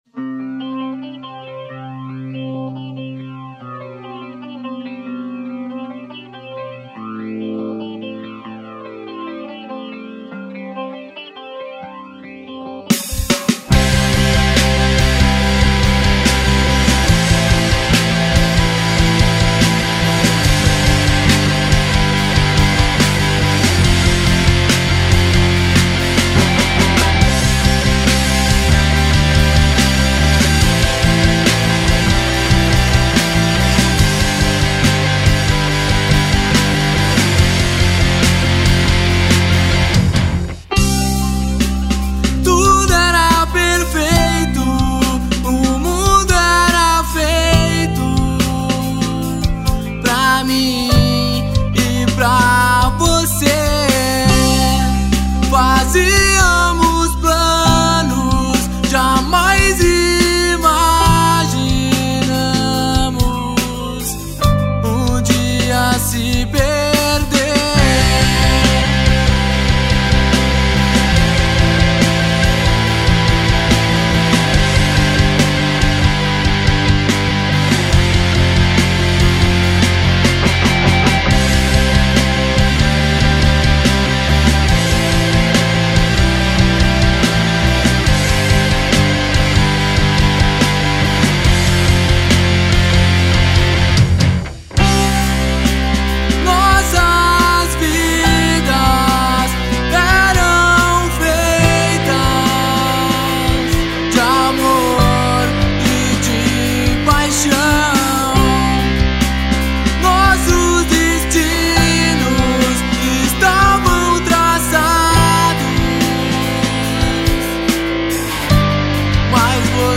EstiloEmocore